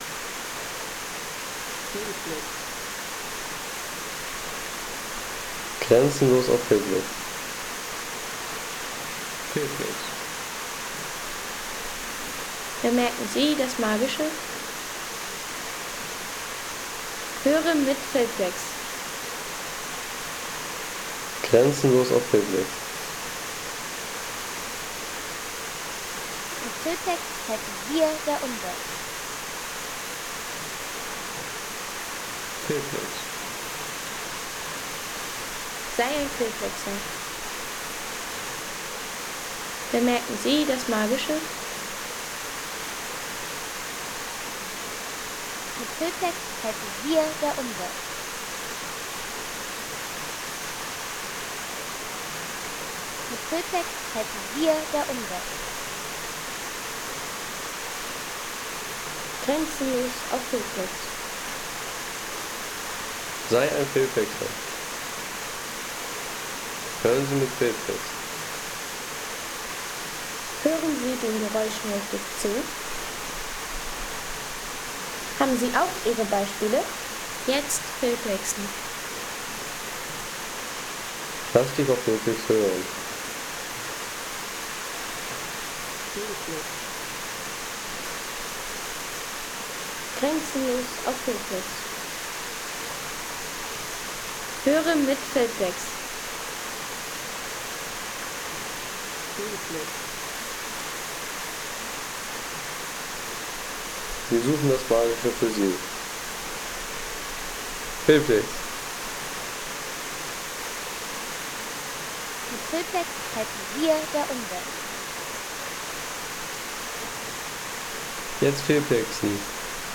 Großer Wasserfall im Tal der Stuibenfälle